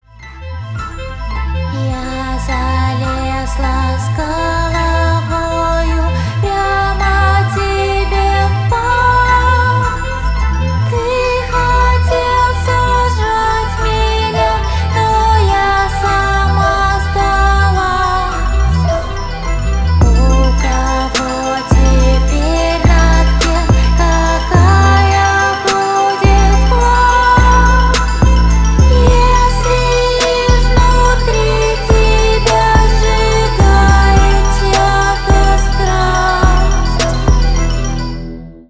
• Категория: Альтернатива
Зарубежные, Инди, Бас, 2017, Круто, Трэп